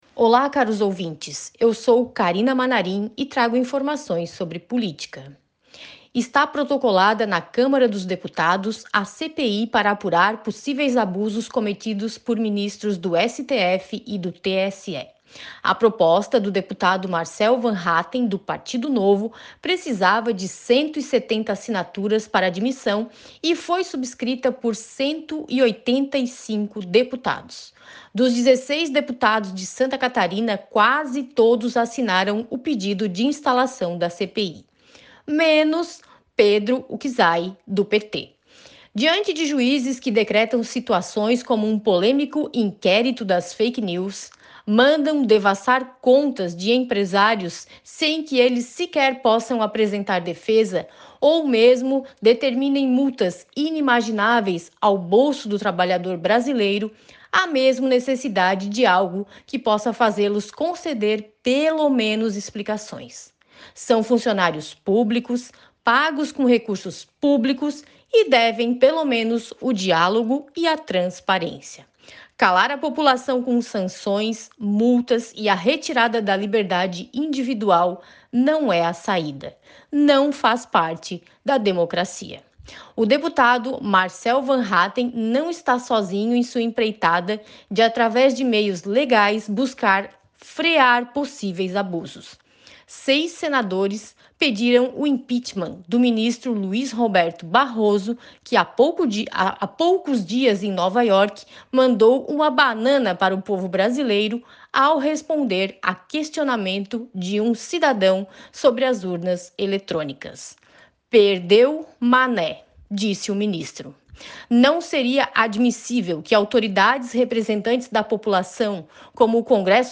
Jornalista comenta sobre o pedido de CPI protocolado e assinado por 185 deputados federais e sobre um pedido de impeachment contra o ministro Luiz Roberto Barroso